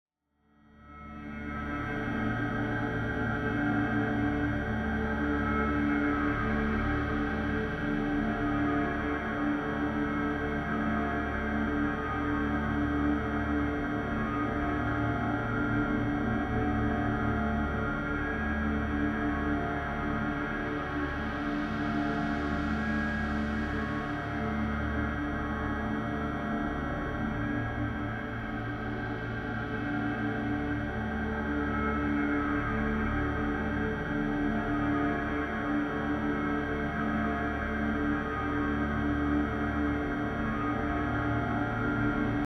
In the ambient construction of Anger, I added the warning sound and the metal tearing sound recorded with an electromagnetic microphone, and added pitch converter, low-pass filter, and smaller reverberation to create a feeling of unease and anger.
Anger_test.wav